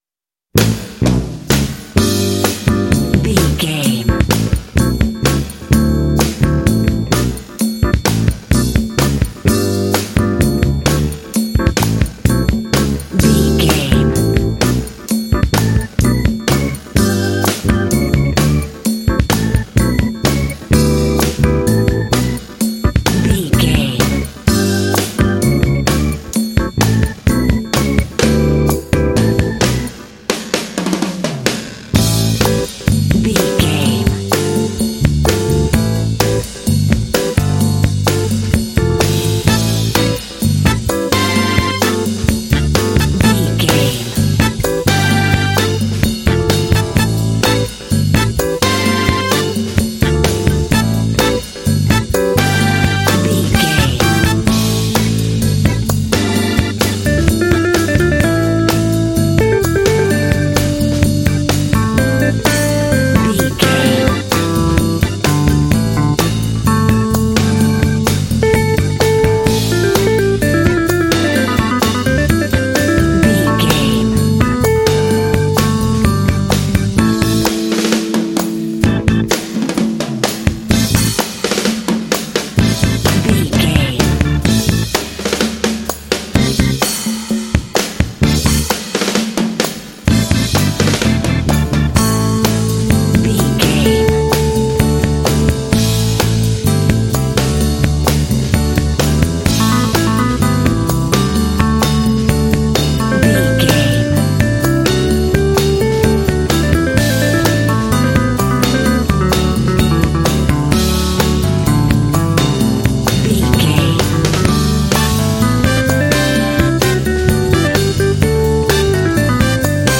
This funky track is great for fun urban-based games.
Aeolian/Minor
funky
smooth
groovy
driving
bass guitar
electric organ
drums
Funk
soul
motown